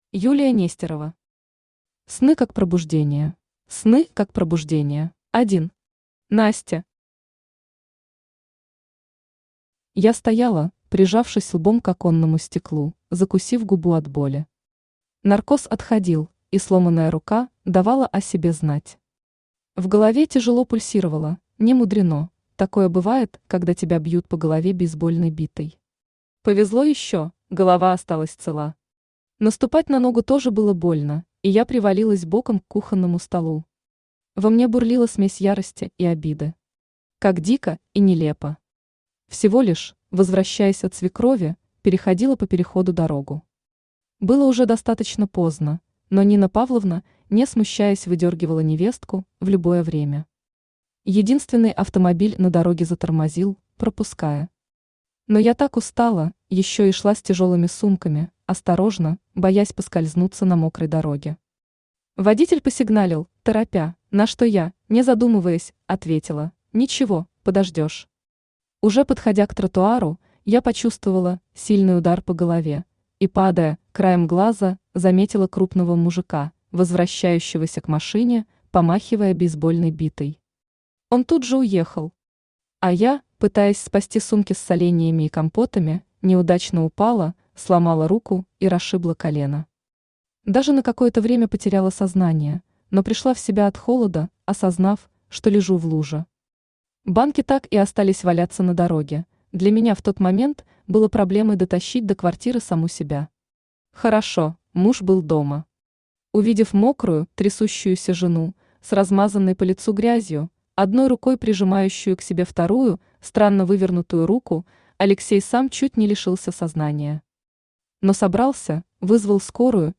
Аудиокнига Сны как пробуждение | Библиотека аудиокниг
Aудиокнига Сны как пробуждение Автор Юлия Нестерова Читает аудиокнигу Авточтец ЛитРес.